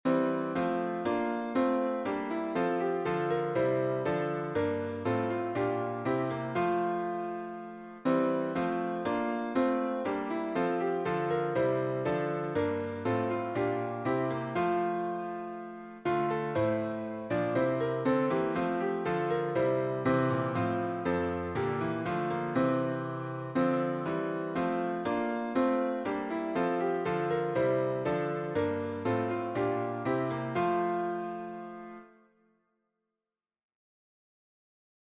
English melody